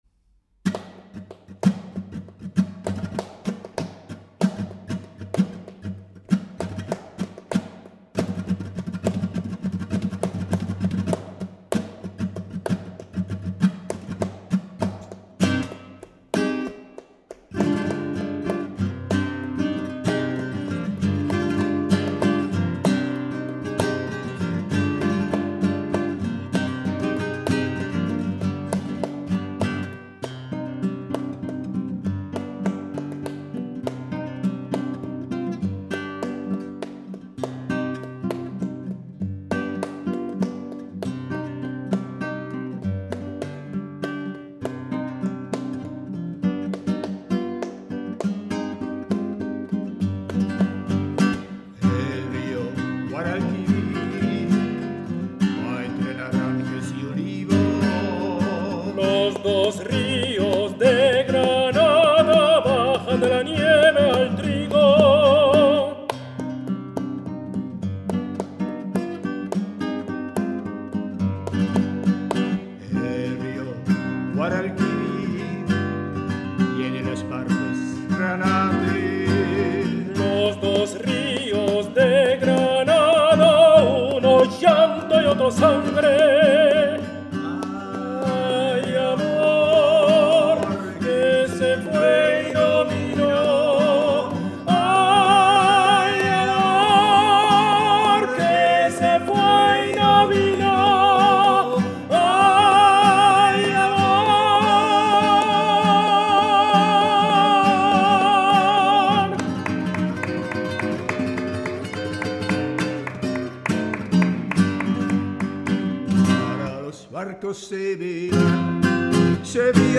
Tenor
Gitarre
Bochum, Studentenkapelle